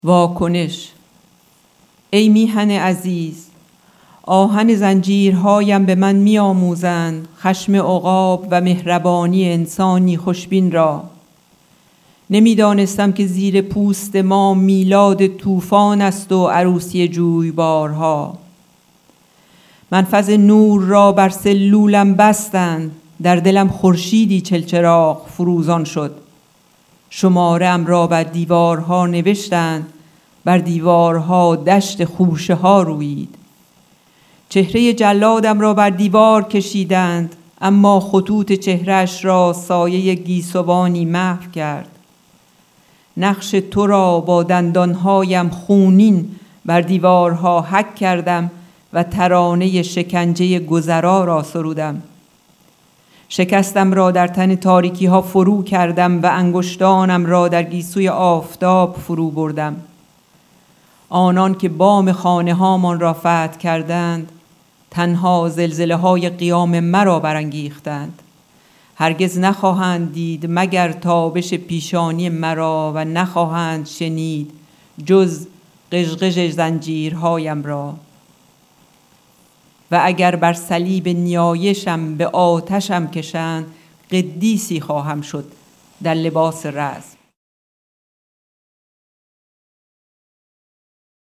دکلمه اشعاری از محمود درویش
قطعه موسیقی به نام صبرا از ژان مارک فوسا و سیلون گرینو